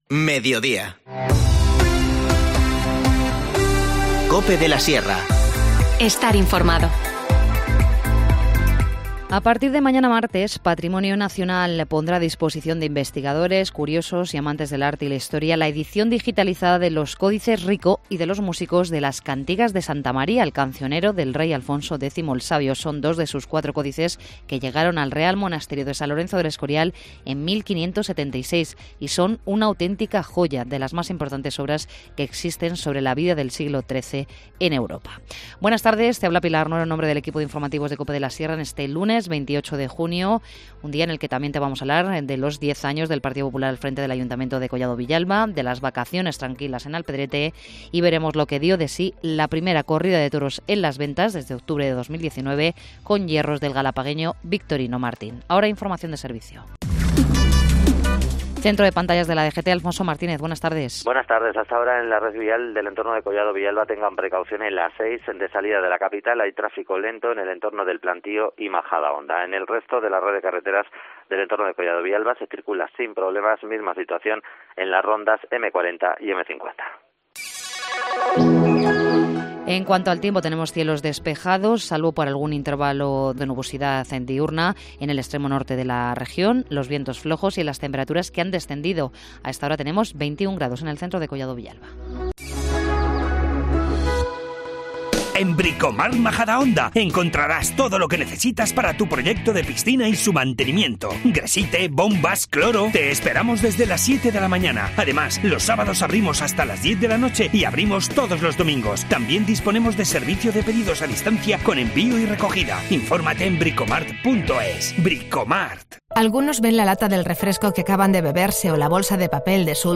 INFORMACIÓN LOCAL